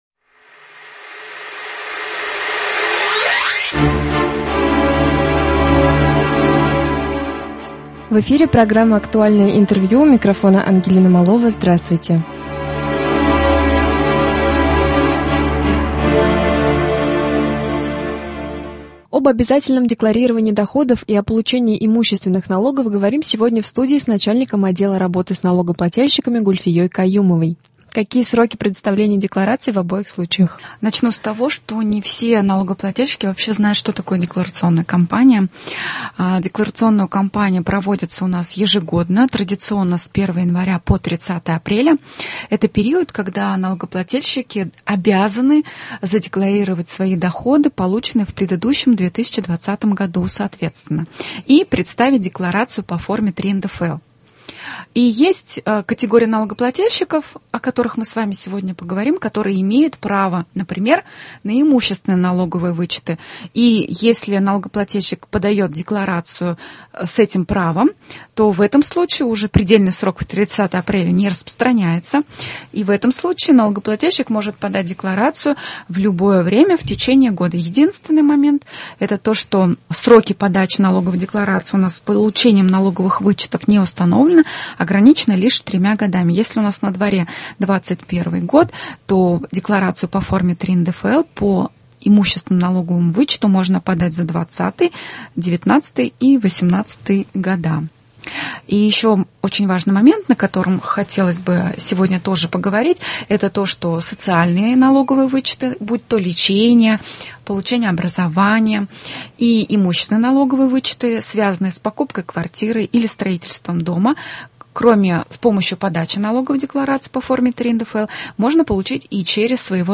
Актуальное интервью (27.04.21)